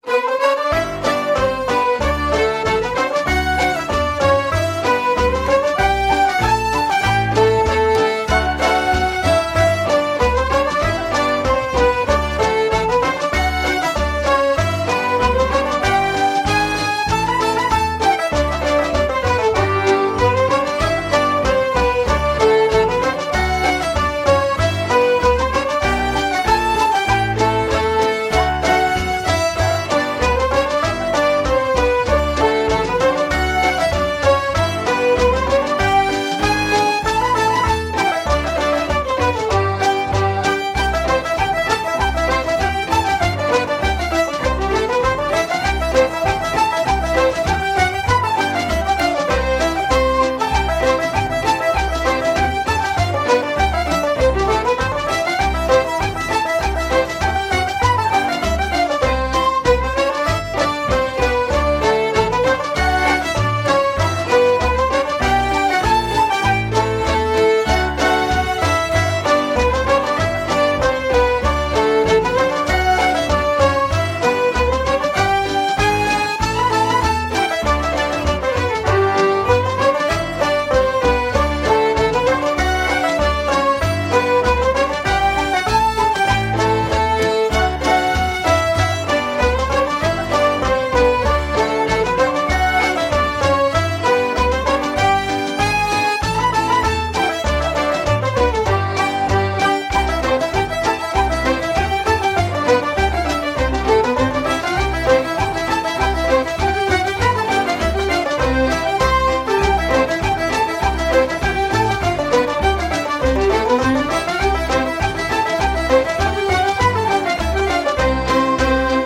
Irish traditional music